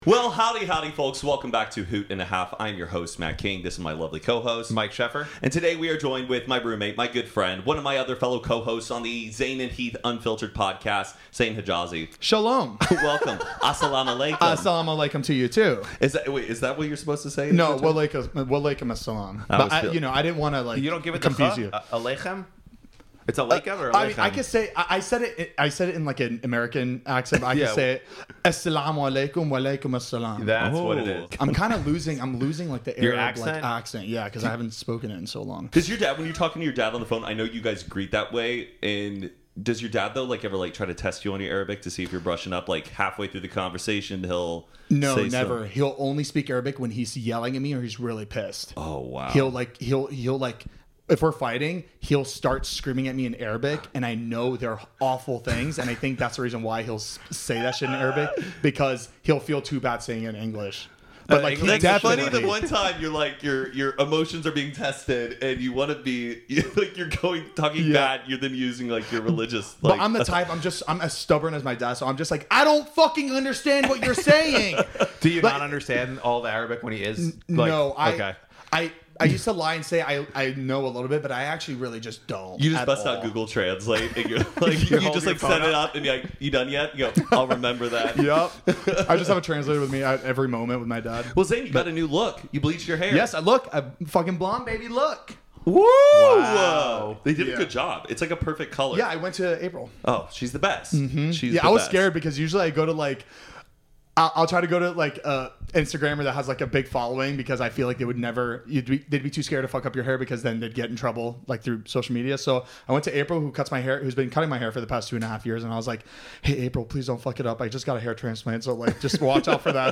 podcast conversation